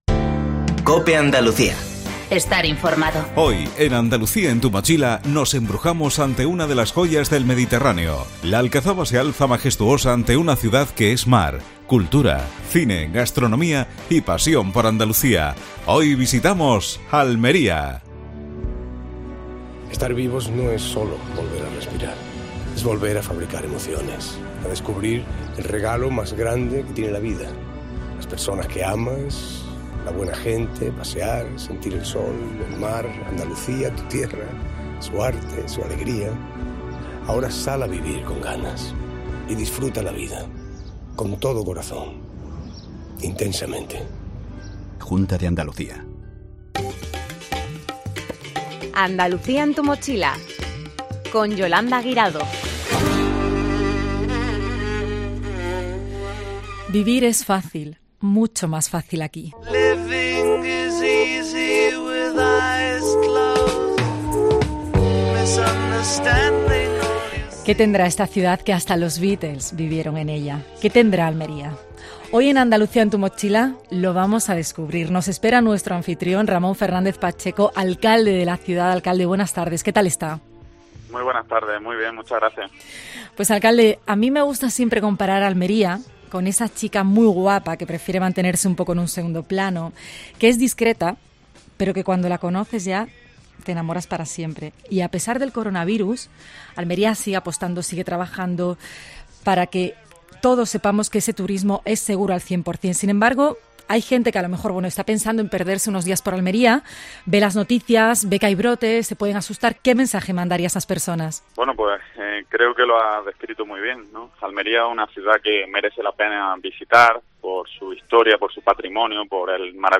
AUDIO: En el programa de esta semana el protagonismo ha sido para Almería. Con la entrevista al alcalde de la capital (Ramón Fernández Pacheco).